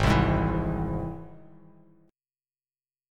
Ab+ Chord
Listen to Ab+ strummed